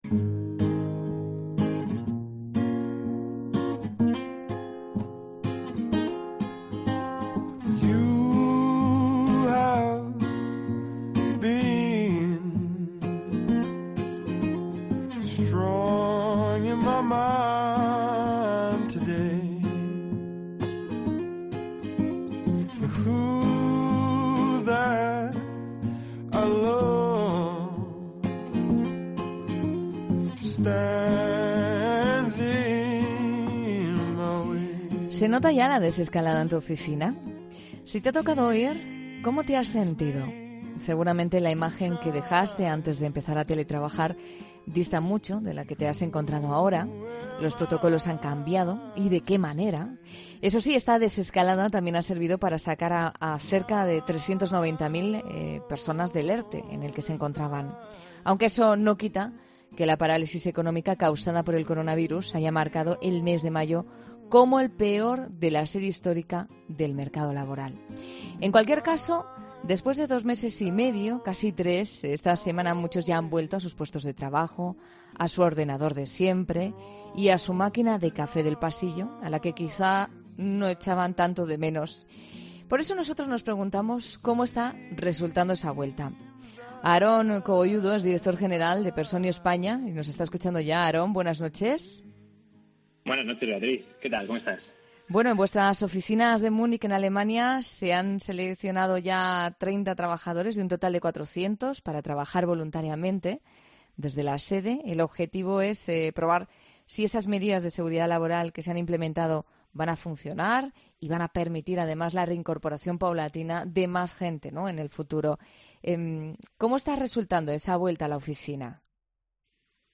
En 'La Noche' de COPE hemos querido conocer cómo se está produciendo la desescalada laboral en las oficinas a través de diversas empresas